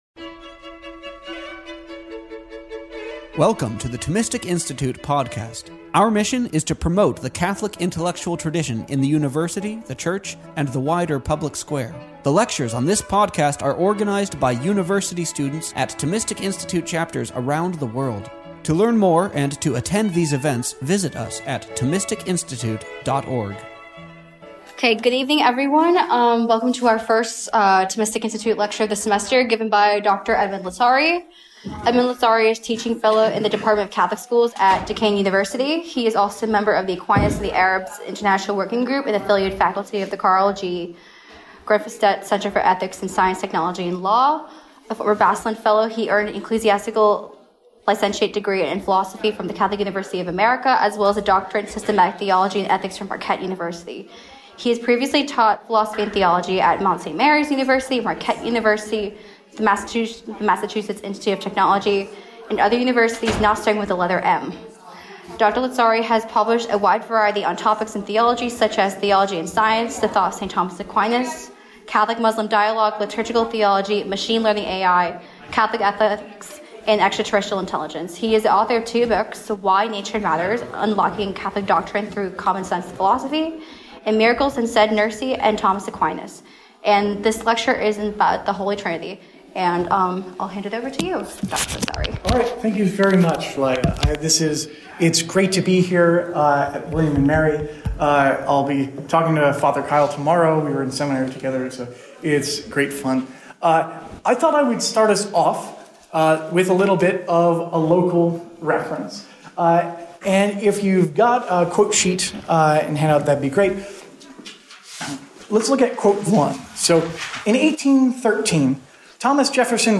A lecture on October 3, 2015 at Fall Thomistic Circles.